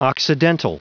Prononciation du mot occidental en anglais (fichier audio)